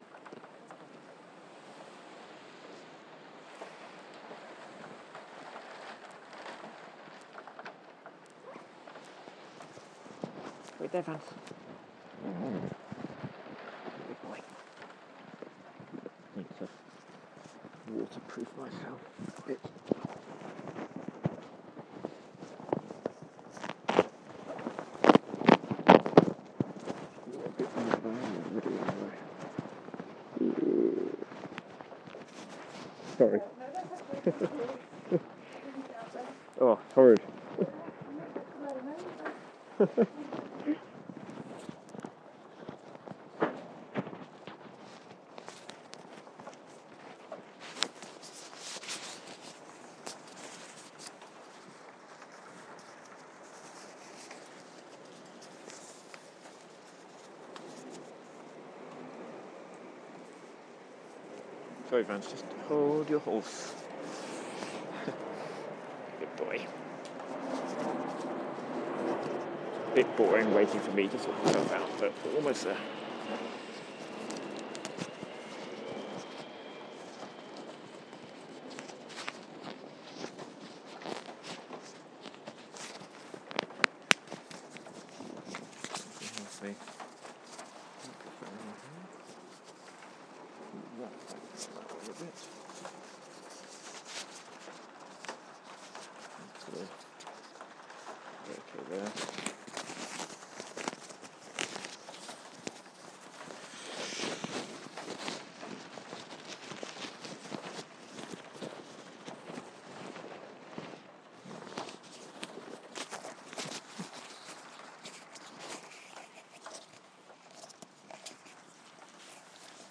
In the hood for a walk in the rain
I get caught in a rain storm on my way to the supermarket and you come along for the ride in the safety of my coat hood.